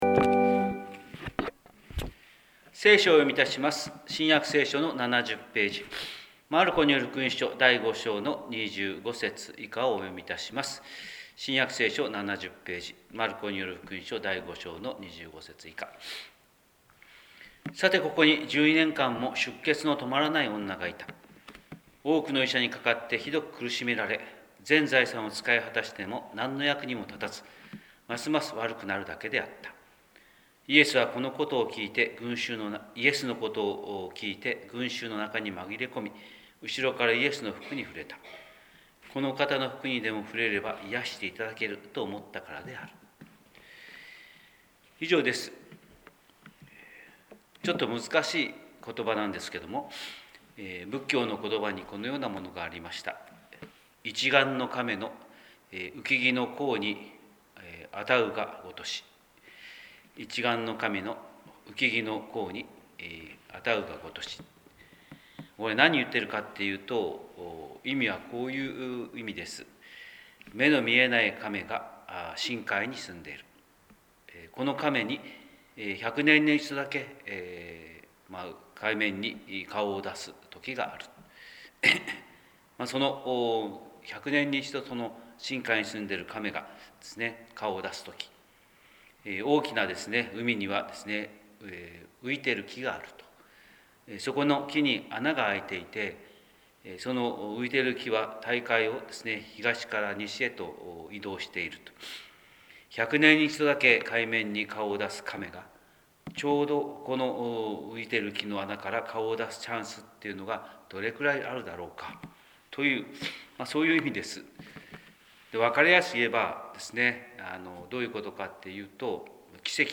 神様の色鉛筆（音声説教）: 広島教会朝礼拝24108
広島教会朝礼拝241108「キリストとの出会いは奇跡」